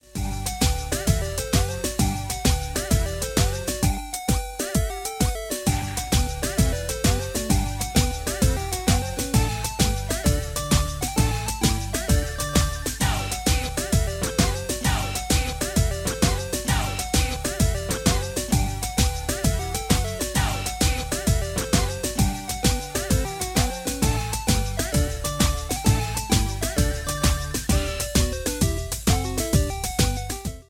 Loopback recorded preview
Fair use music sample